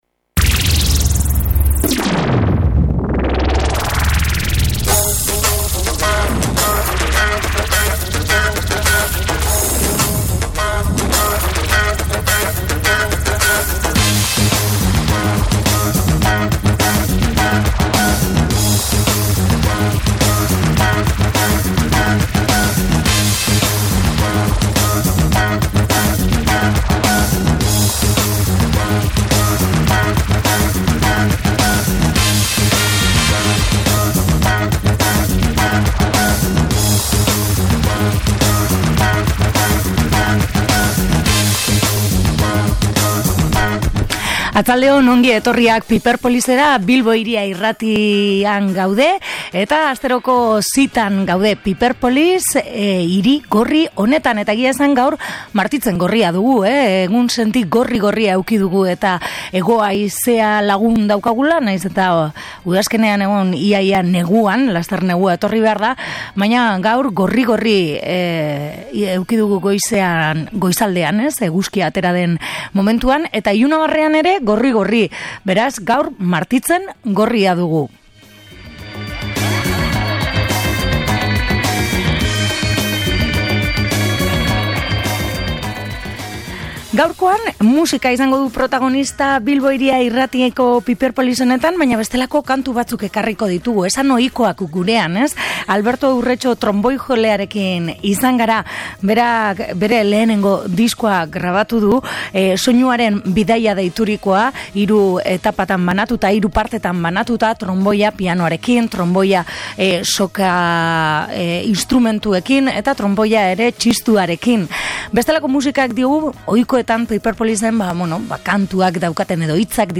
Gaurkoan bestelako musika entzuteko aukera izan dugu